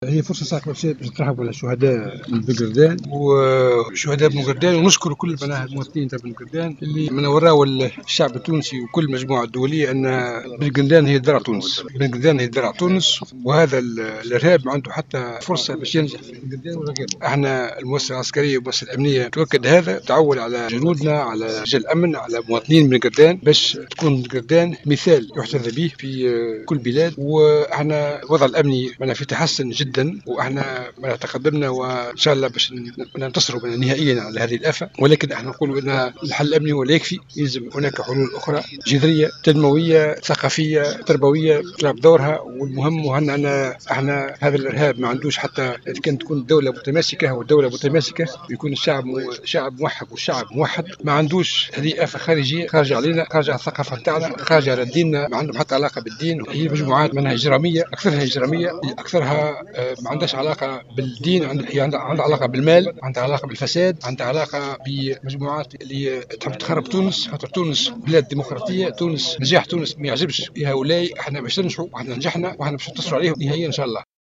وصف اليوم الثلاثاء وزير الدفاع فرحات حرشاني مدينة بن قردان بـ "درع تونس"، على هامش احياء ذكرى ملحمة 7 مارس وخلال موكب رسمي للترحّم على أرواح الشهداء بمقبرة بن قردان.
وقال في تصريح لـ "الجوهرة أف أم" إن أهالي بنقردان مثال يحتذى في الدفاع عن تونس مؤكدا أهمية دور القوات المسلحة في مواجهتها الارهاب. وأكد في سياق متصل، أن الوضع الأمني في تحسّن كبير مضيفا أن الحل الأمني غير كاف بمفرده مشددا على أهمية التنمية والثقافة والتعليم.